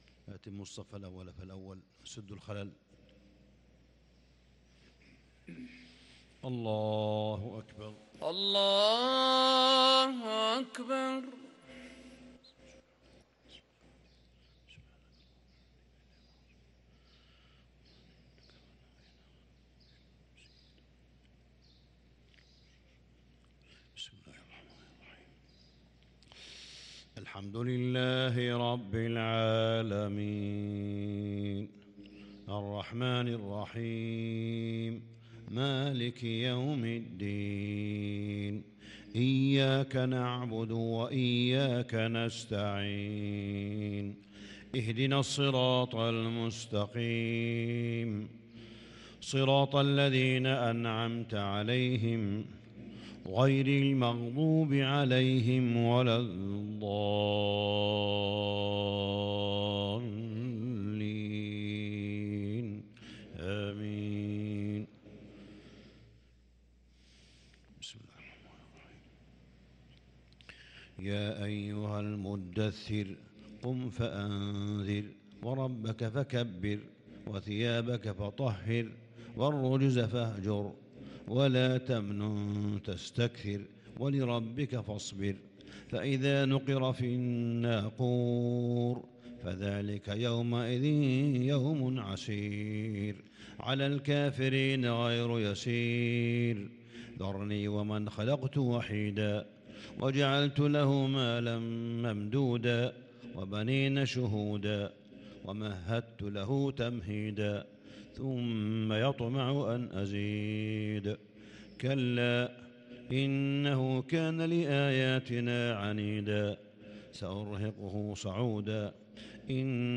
صلاة الفجر للقارئ صالح بن حميد 9 رمضان 1443 هـ
تِلَاوَات الْحَرَمَيْن .